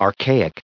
added pronounciation and merriam webster audio
75_archaic.ogg